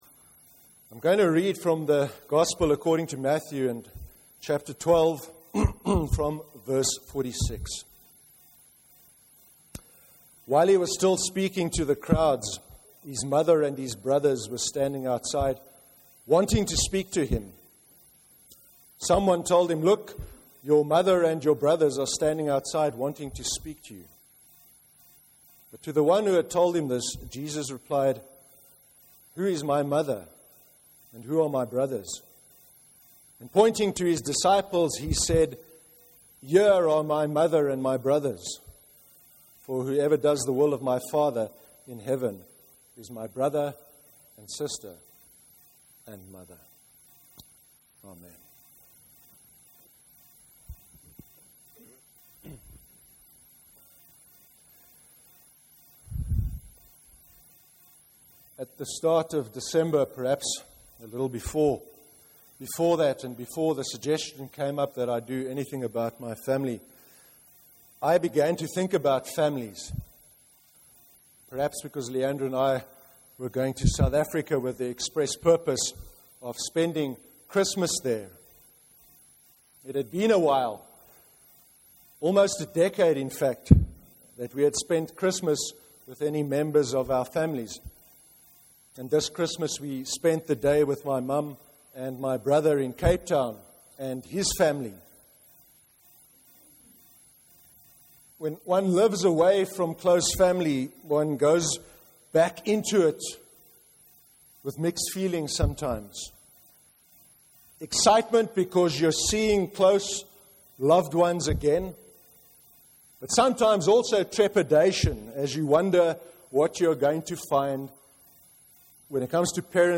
05/01/2014 sermon. “Family” (Matthew 12:46-50 and Mark 3:19-21)
A recording of the service’s sermon is available to play below, or by right clicking on this link to download the sermon to your computer.